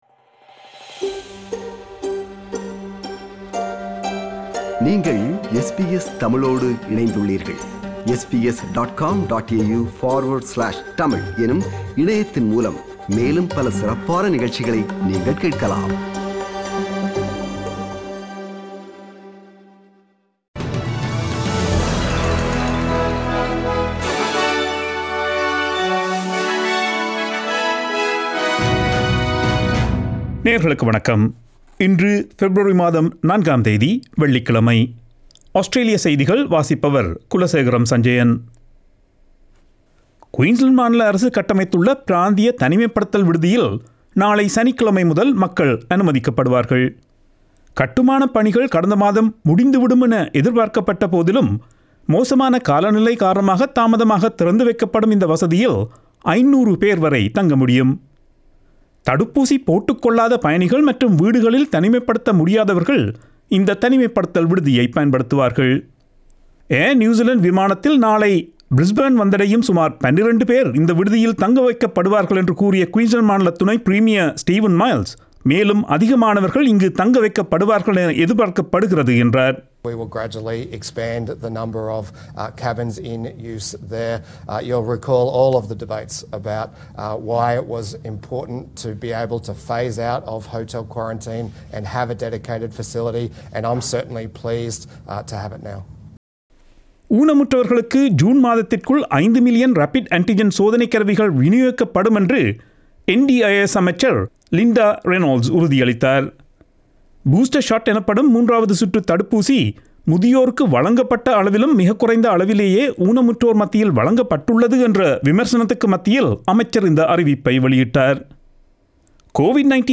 Australian news bulletin for Friday 04 February 2022.